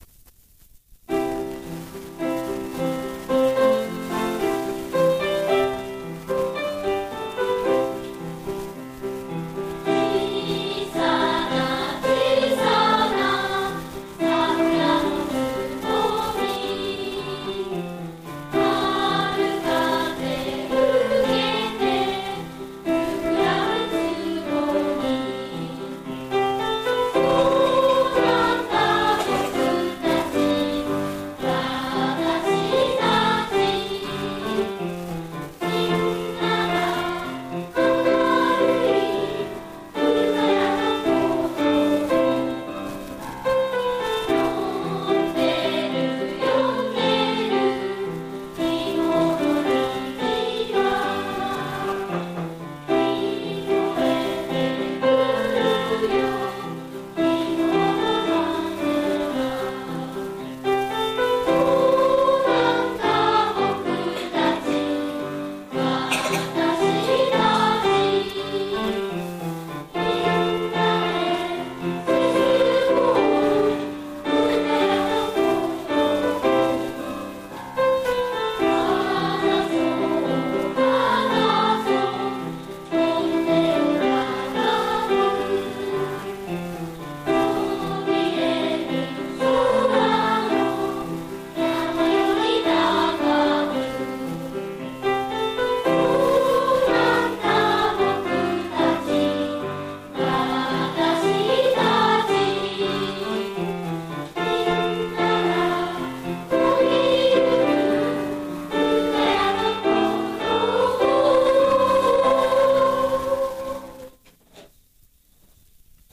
実際の歌も子供たちの声で聞くことができますので、何回も聞いて歌えるようにしておけるといいですね。
愛唱歌（歌入り）　⇐クリック